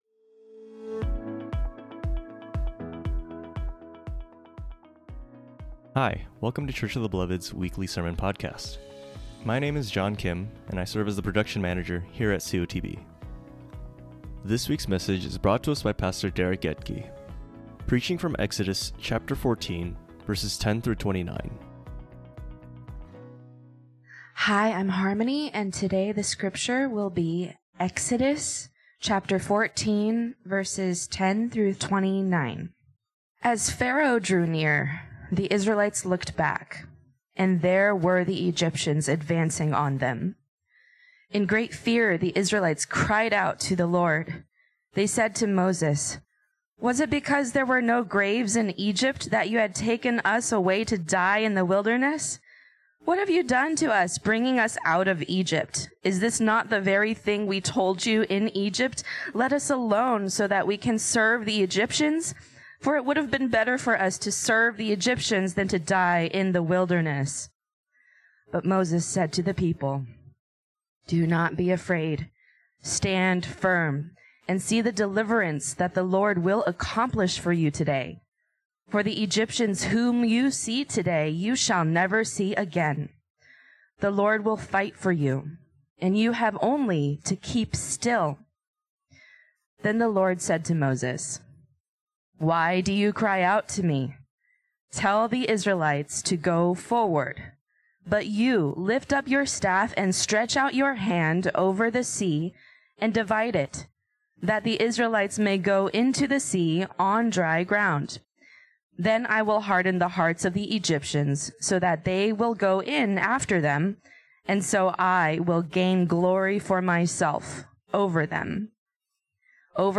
preaches from Exodus 14:10-31